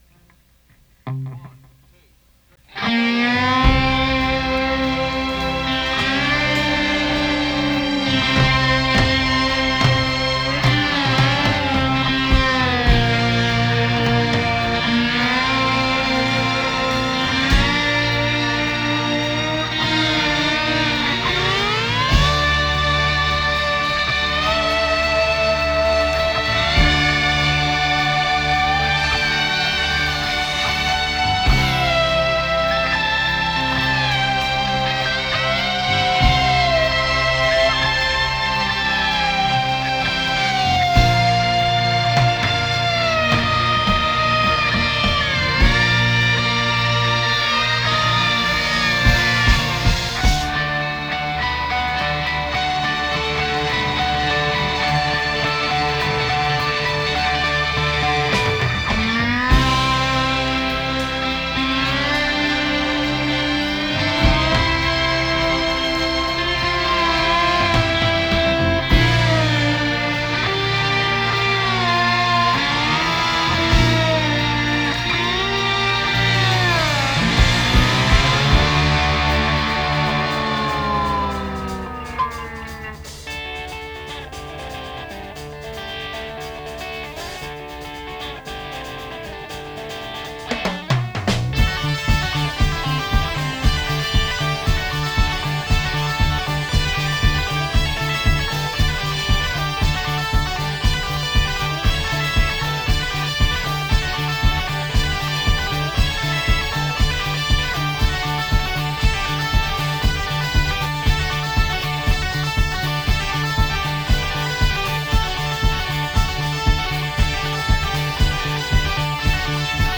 That is quite evident in the style of the song.
I used a Marshall half stack amplifier.
guitar and vocals (lower voice)
rhythm guitar and vocals (higher voice)
mellotron and synthesizer
drums
bass
Backing vocals